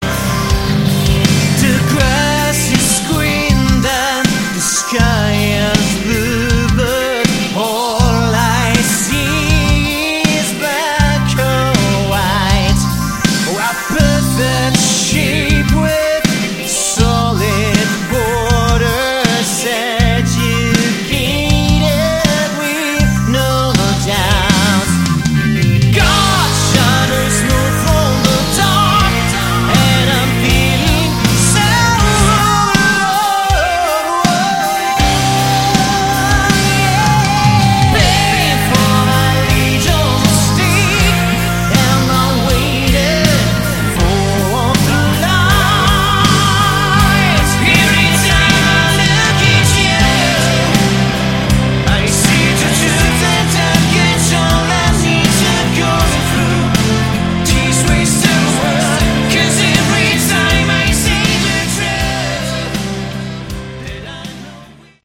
Category: AOR / Melodic Rock
lead, backing vocals
guitar, backing vocals
bass, backing vocals
keyboards, backing vocals
drums, percussion